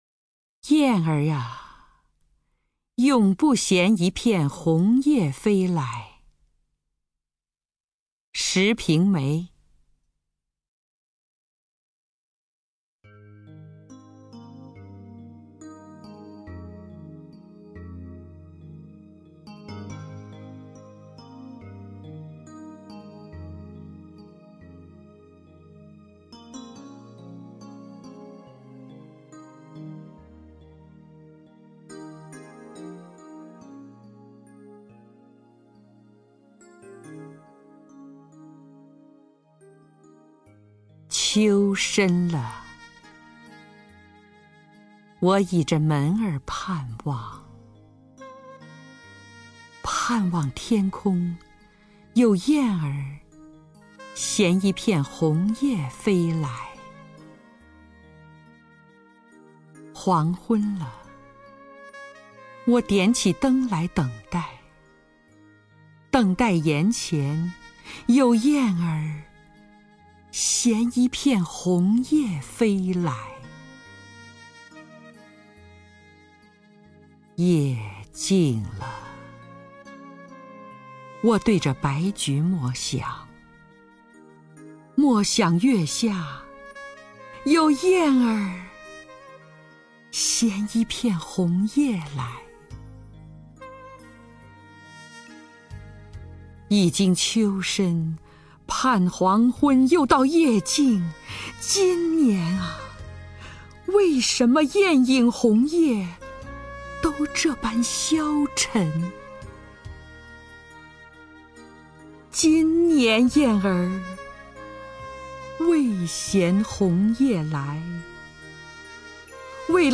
张筠英朗诵：《雁儿呵，永不衔一片红叶再飞来！》(石评梅)